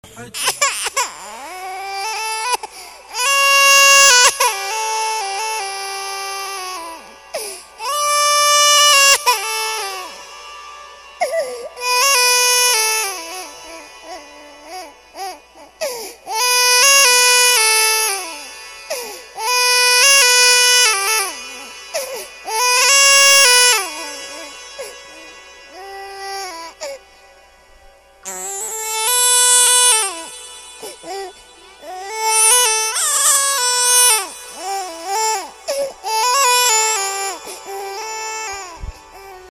BEBE DJ BABY CRY
Tonos EFECTO DE SONIDO DE AMBIENTE de BEBE DJ BABY CRY
bebe_dj_baby_cry.mp3